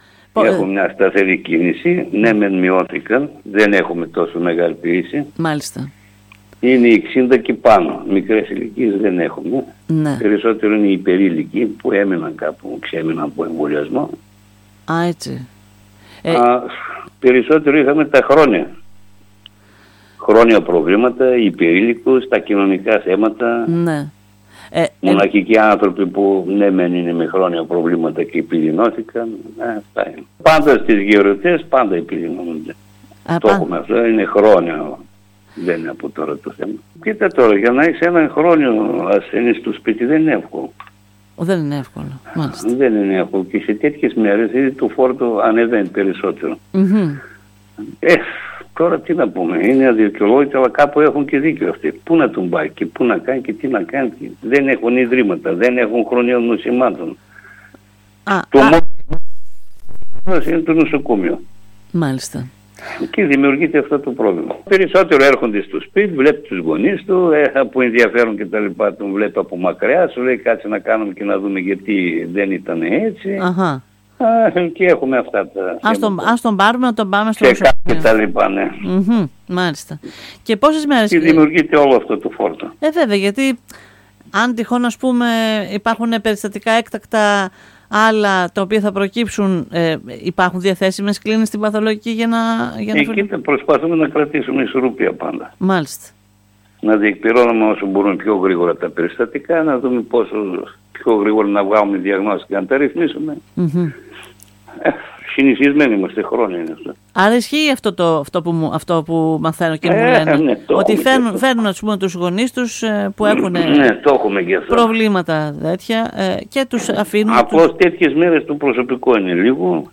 Μιλώντας στην ΕΡΤ Ορεστιάδας σημείωσε πως στο διήμερο των Χριστουγέννων η κίνηση και ο φόρτος της Παθολογικής κλινικής ήταν μεγάλος, από ασθενείς υπερήλικες με χρόνια προβλήματα ή  και μοναχικούς ανθρώπους με τα ίδια θέματα υγείας που συσσωρεύθηκαν στο Νοσοκομείο από συγγενικά τους πρόσωπα που τους έφεραν εκεί επειδή τώρα τα συμπτώματα  διαπιστώθηκε ότι επιδεινώθηκαν.